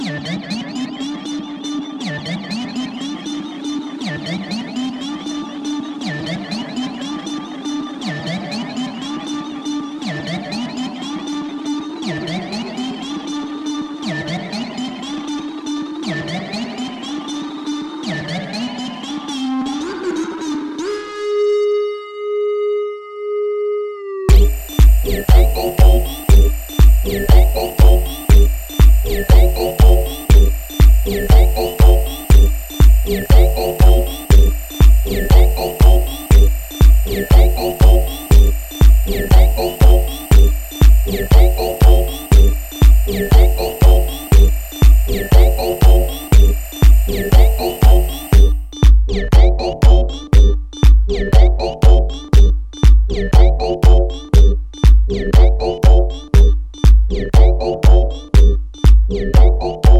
遊び心や実験性を感じさせる